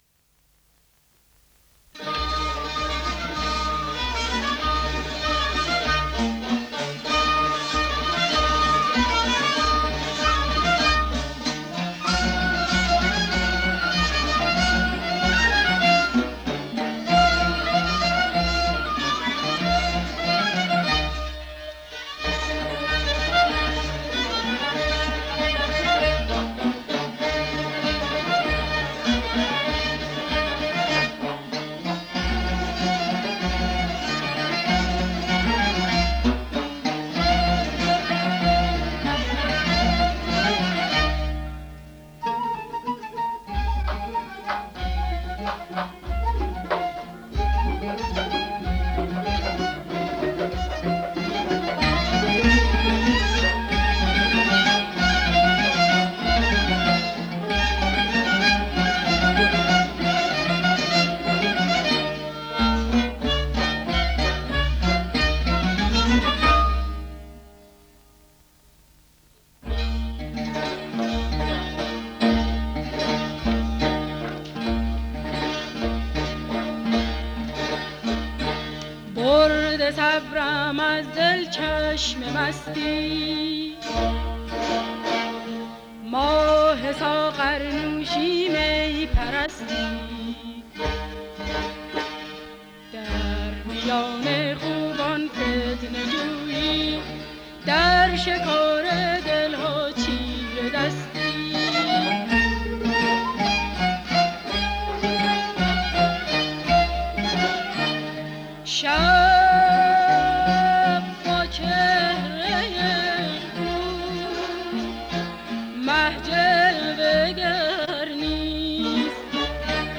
با صدای بانوان